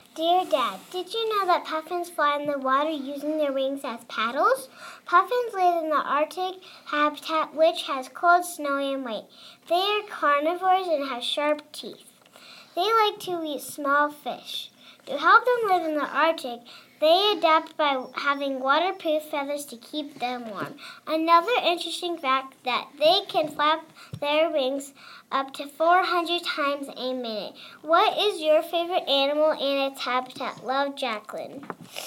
Puffin